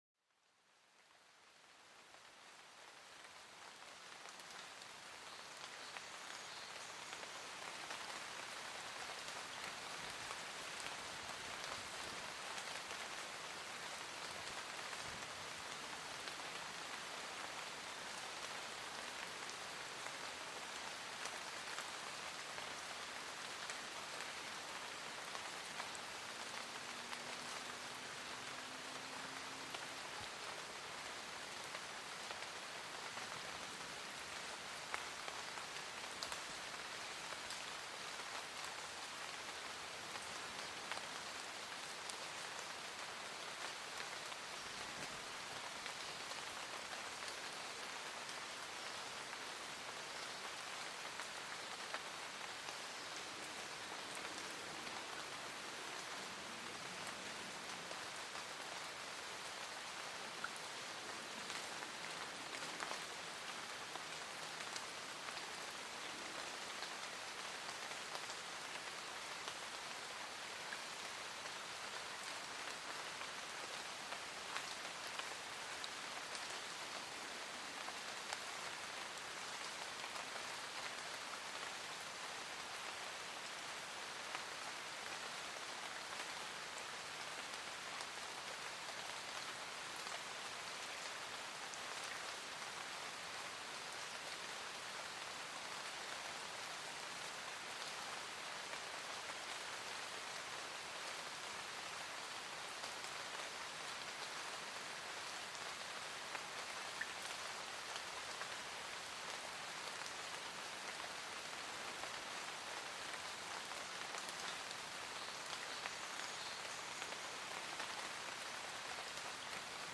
Pluie en Forêt: Une Douce Mélodie pour l'Âme
Plongez au cœur d'une forêt mystérieuse où la pluie tisse une mélodie apaisante sur le feuillage dense. Laissez-vous envelopper par le son harmonieux des gouttes d'eau, qui transforme la forêt en un havre de paix et de sérénité.